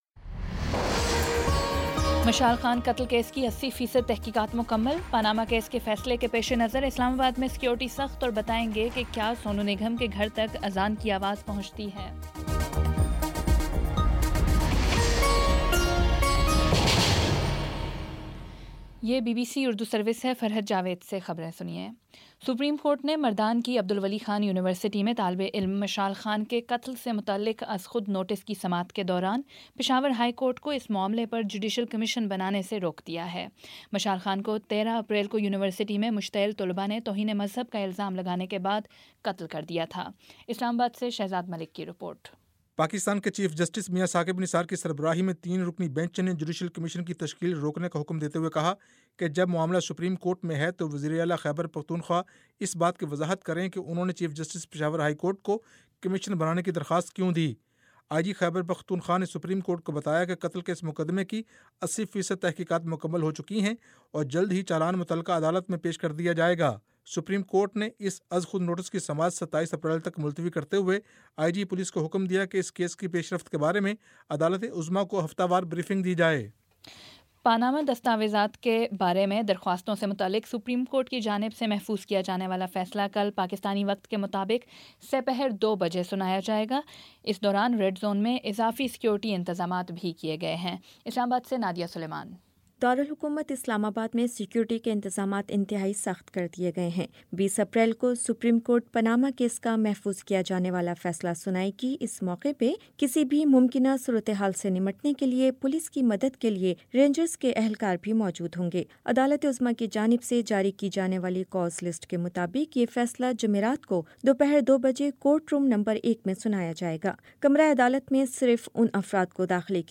اپریل 19 : شام چھ بجے کا نیوز بُلیٹن